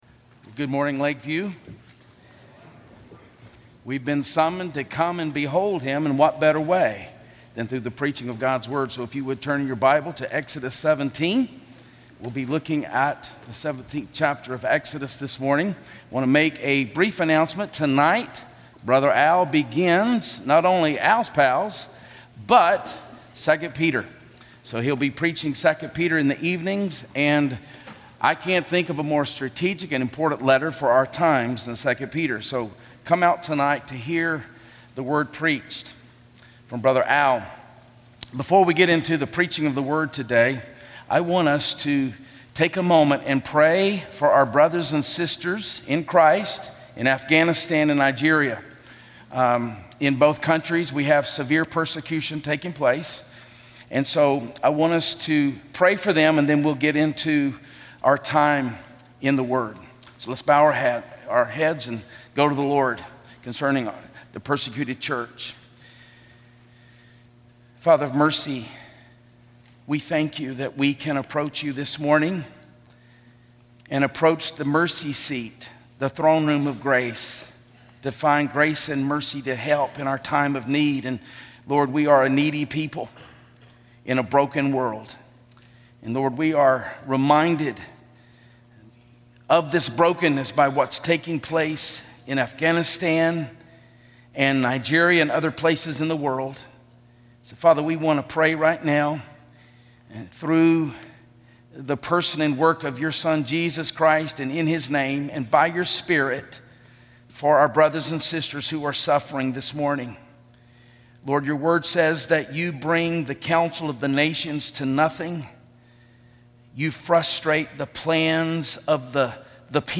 Series: Stand Alone Sermons
Service Type: Sunday Morning